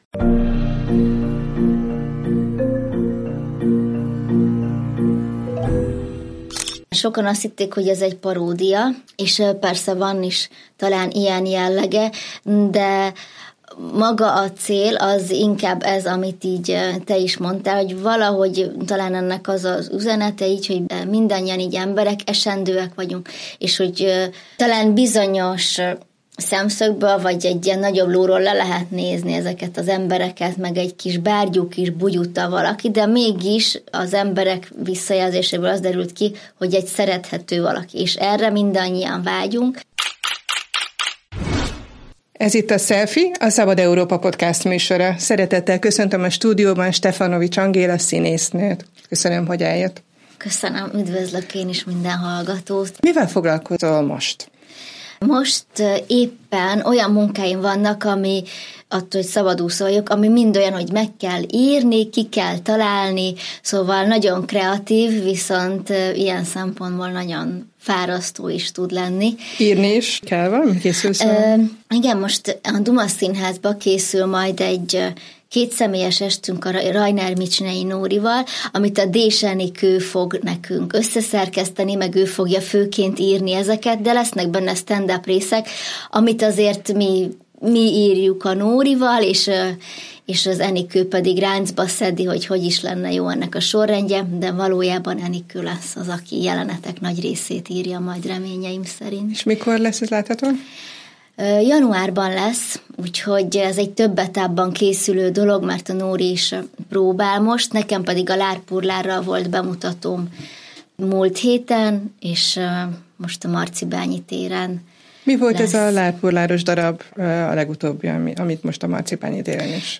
Erdész és gyógypedagógus is szeretett volna lenni, de nem voltak elég jók a jegyei. Megnyert szavalóversenyek és drámatagozatos középiskola után egyenes út vezetett a színművészeti főiskolára. A Szelfi vendége Stefanovics Angéla Jászai Mari-díjas színésznő, forgatókönyvíró volt.